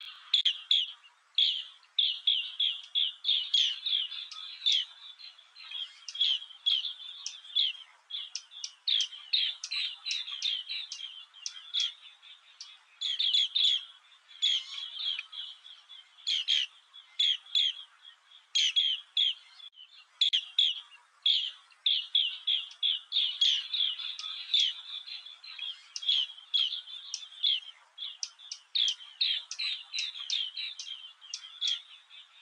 绿喉蜂虎鸟叫声户外采集